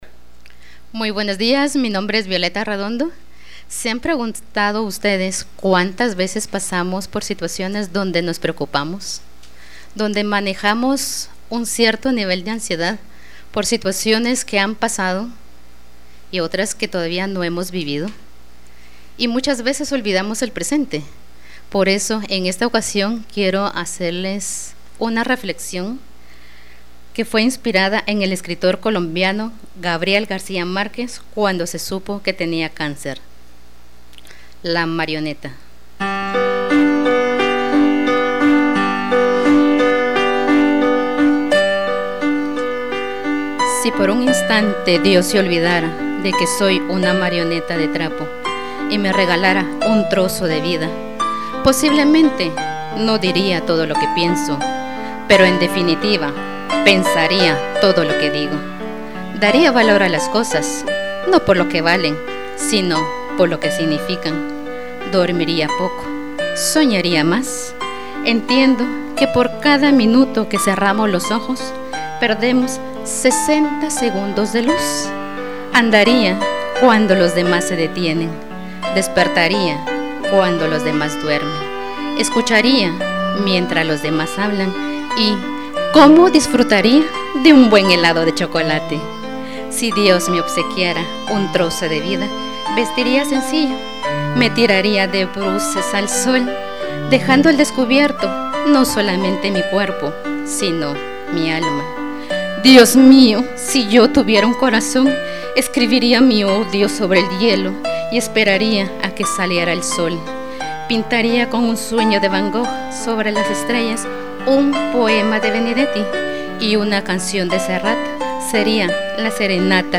2020 Arte y Cultura Locución 0 Para reflexionar hoy.